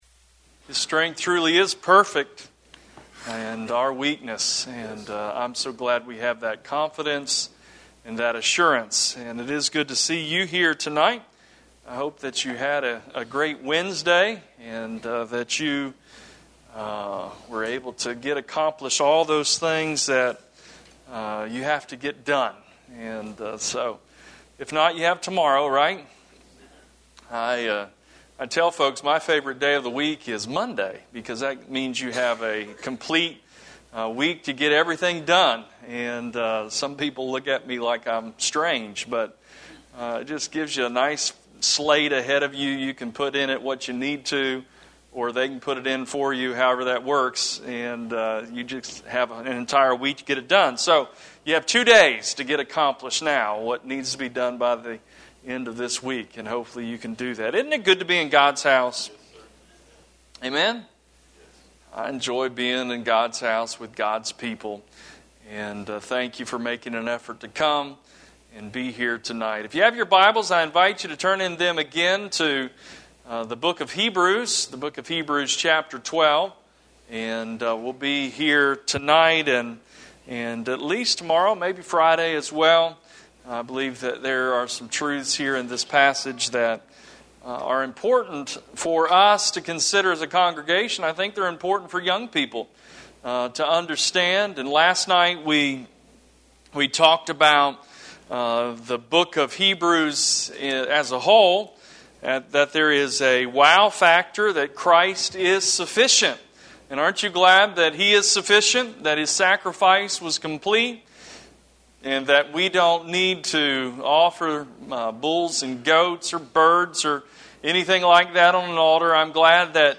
Series: Youth Revival 2019